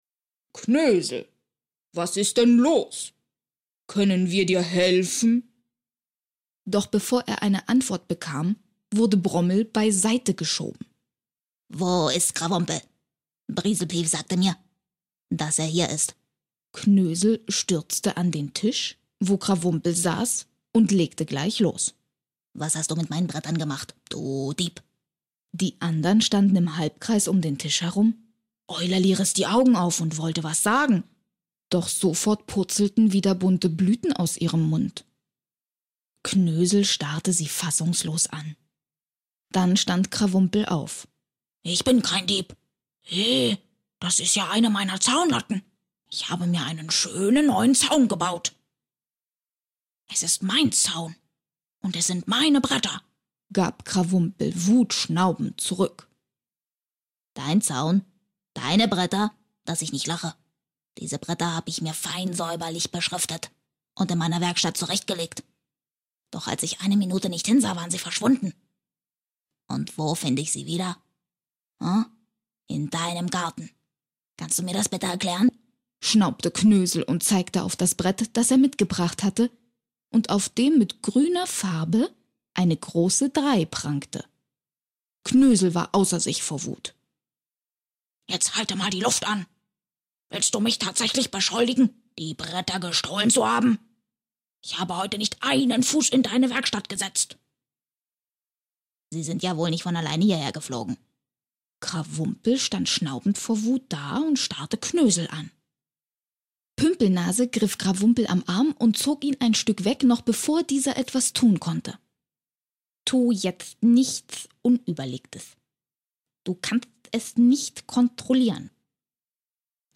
Sprecherin für Hörbücher